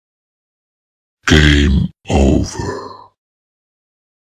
Game Over Sound
meme